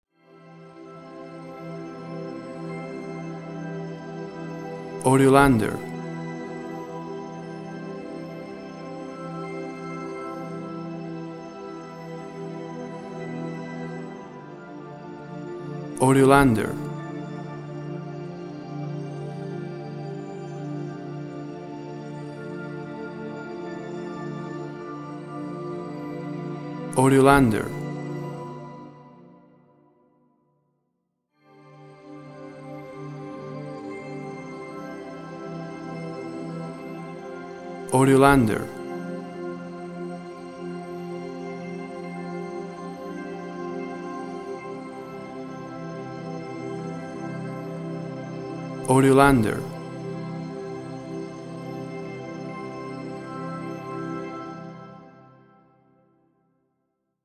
Melodic space music — vast, warm, soothing (mid range).
Tempo (BPM): 60